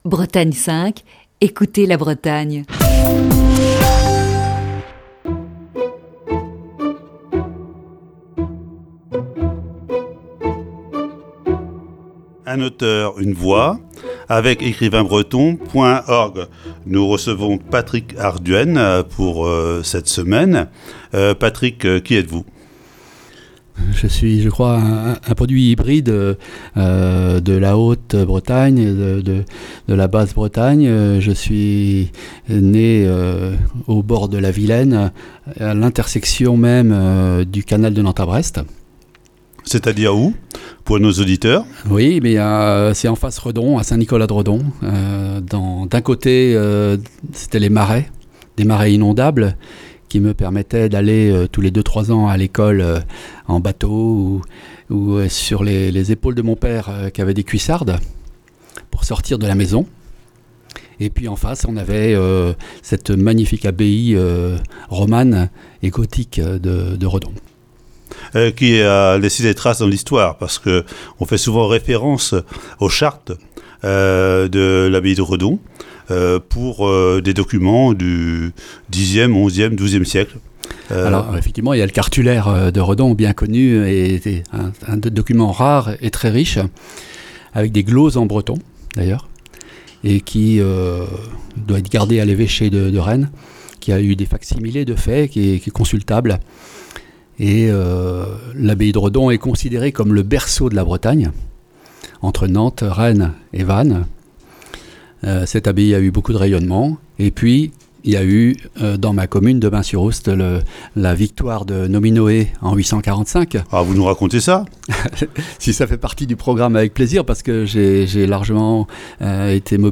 Voici ce lundi, la première partie de cet entretien.